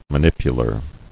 [ma·nip·u·lar]